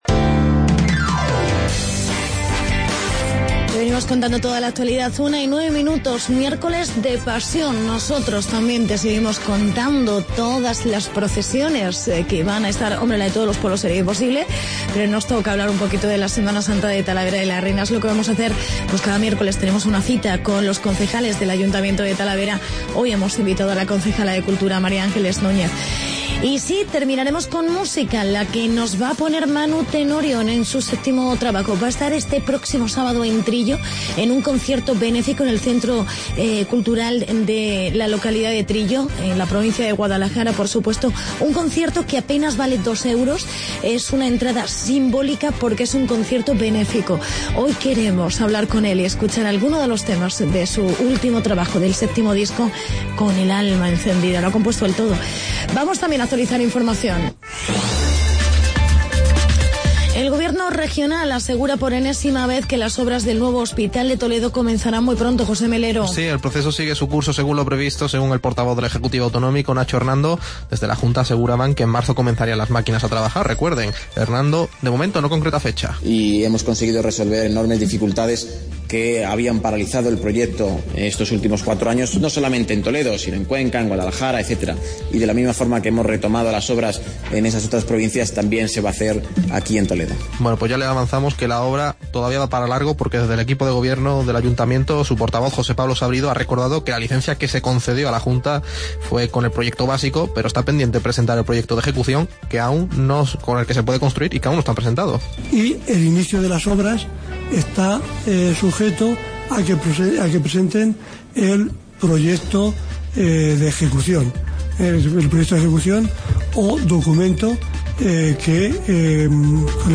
Hablamos de la Semana Santa de Talavera con la concejal Mª Angeles Núñez y entrevistamos al cantante Manu Tenorio.